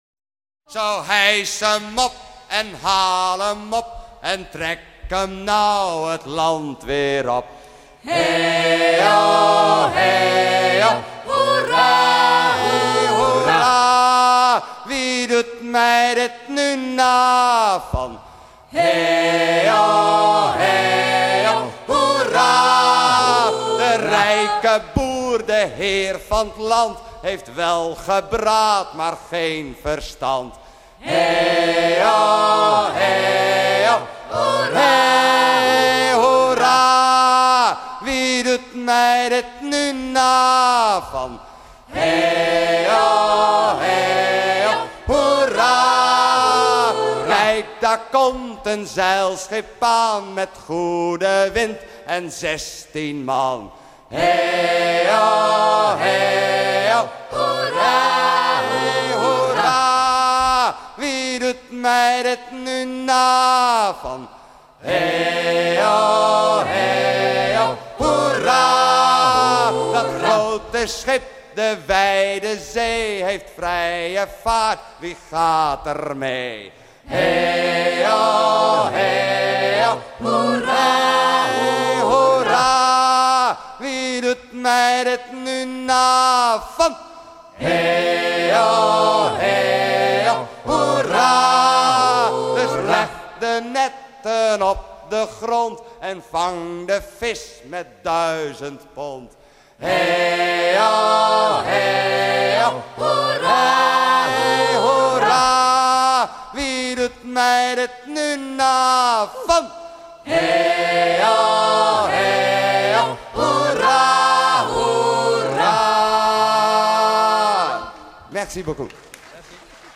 chant rythmant le travail des pêcheurs des îles de la Frise rentrant leurs filets
Pièce musicale éditée